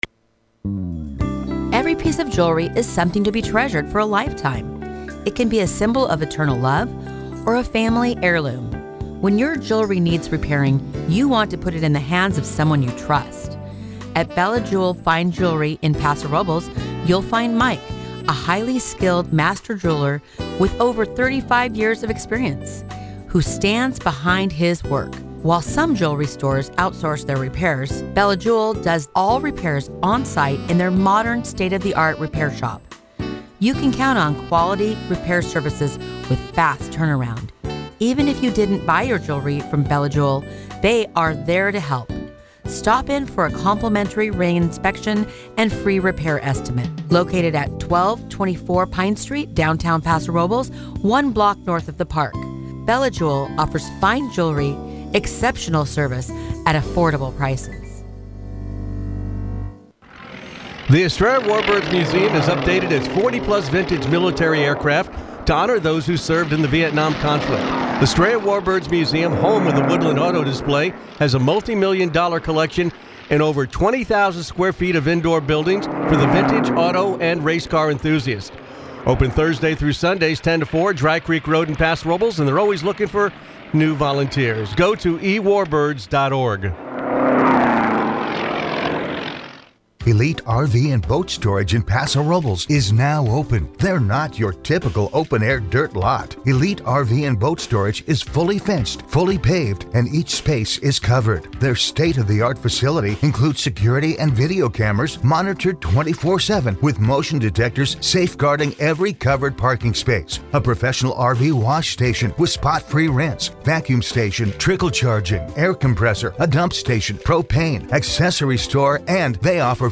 The Morning Exchange; North County’s local news show airs 6 a.m. to 9 a.m. every weekday.